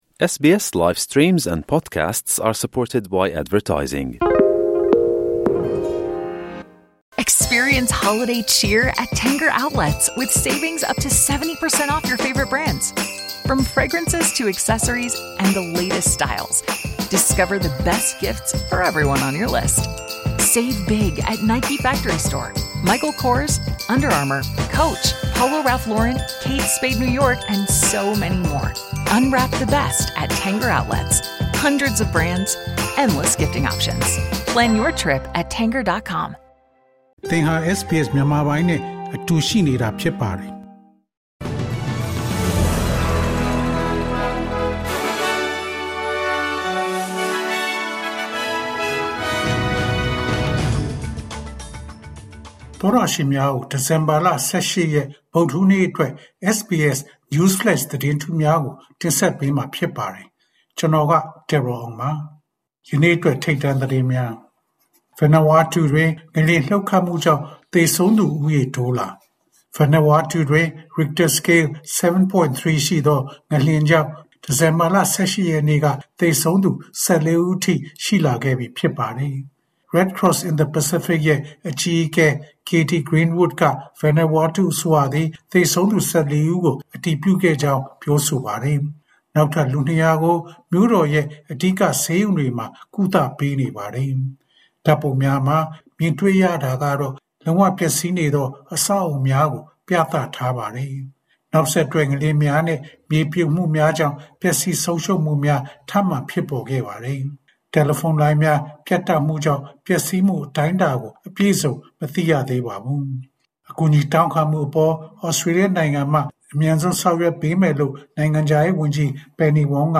ဒီဇင်ဘာလ ၁၈ ရက် ဗုဒ္ဓဟူးနေ့ SBS Burmese News Flash သတင်းများ။